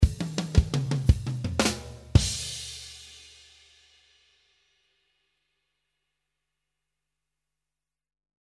This is a shuffle groove in 113 bpm.
You can use this style of drum loops, building irish music style because of the shuffle groove.